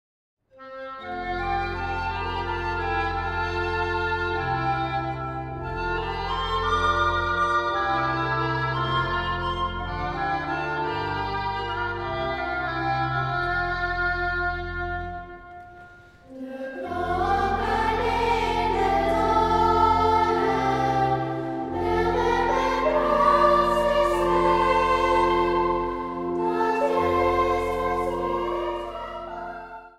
Zang | Gemengd koor
Zang | Kinderkoor